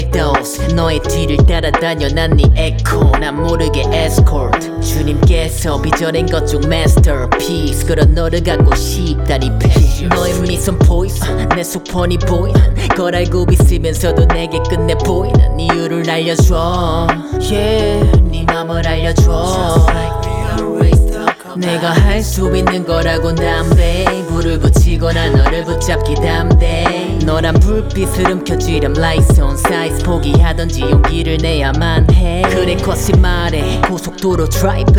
Жанр: Рэп и хип-хоп
# Korean Hip-Hop